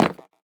Minecraft Version Minecraft Version 25w18a Latest Release | Latest Snapshot 25w18a / assets / minecraft / sounds / block / nether_bricks / step6.ogg Compare With Compare With Latest Release | Latest Snapshot
step6.ogg